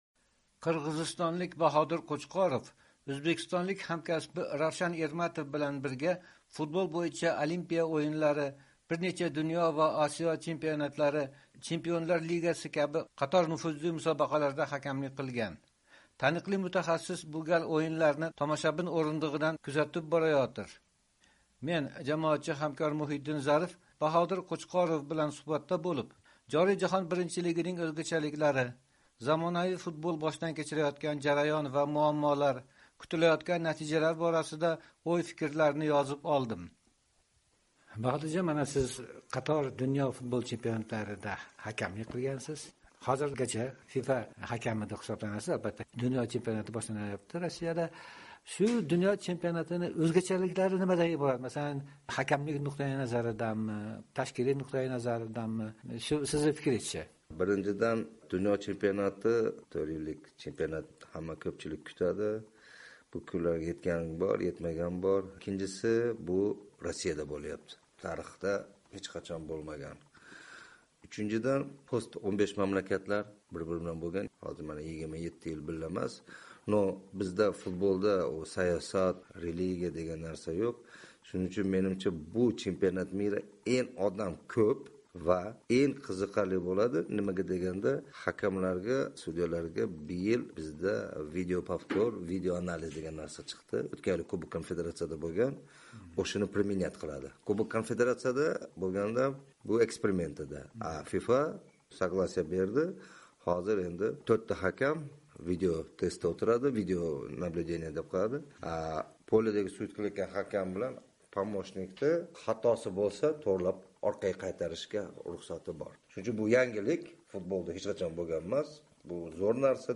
suhbat